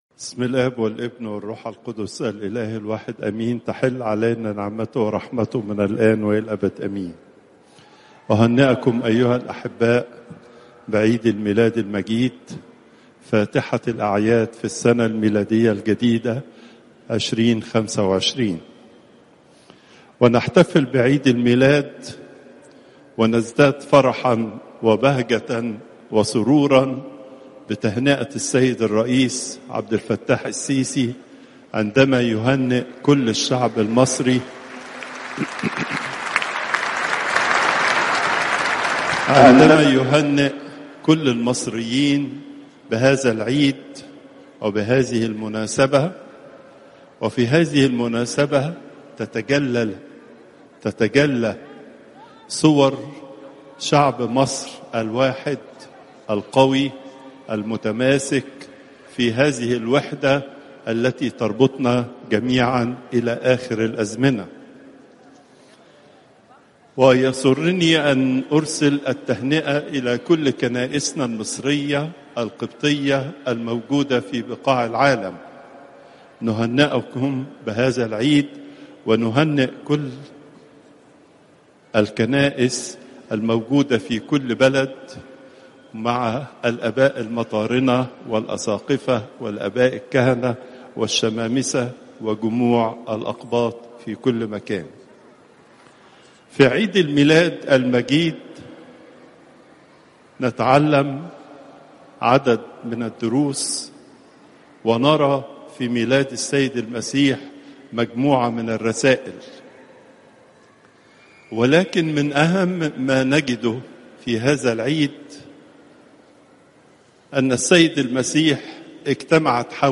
Popup Player تحميل الصوت البابا تواضروس الثانى الثلاثاء، 07 يناير 2025 21:09 المحاضرة الأسبوعية لقداسة البابا تواضروس الثاني الزيارات: 306